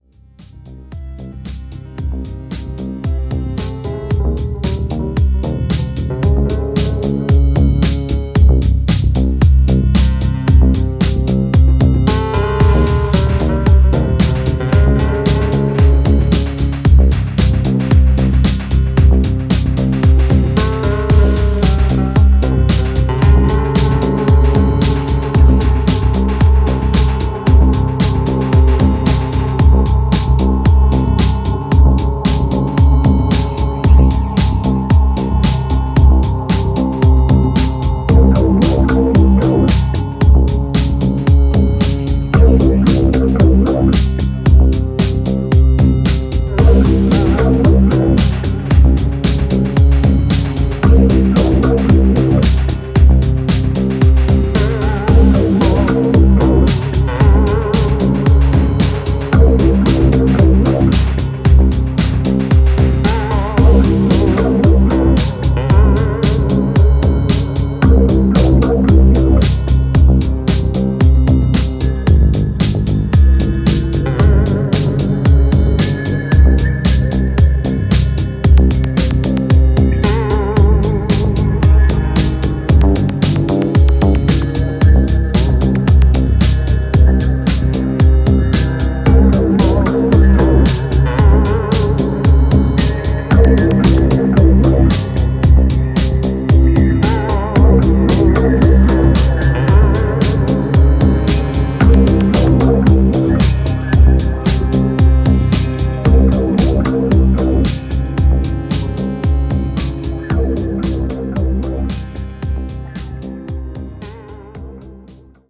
Romanian duo
Genre Electronica , Leftfield